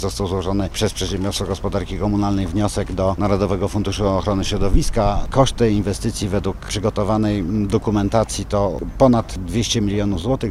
– Mówi prezydent Koszalina, Piotr Jedliński.